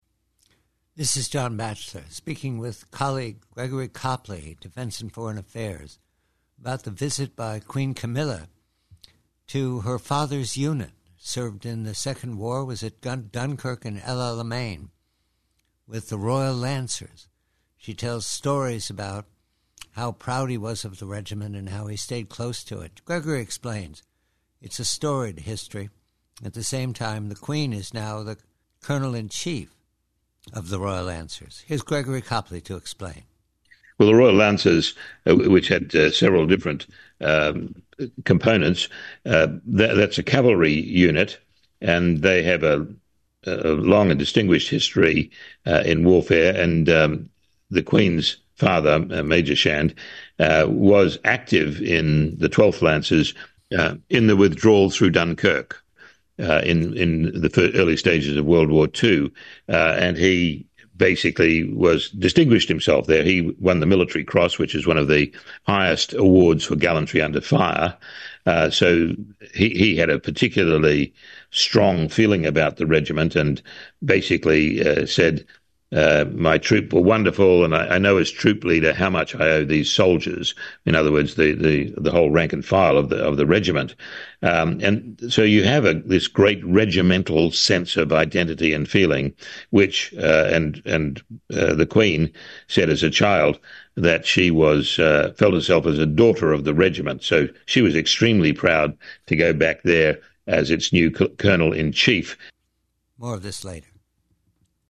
PREVIEW: CROWN: Conversation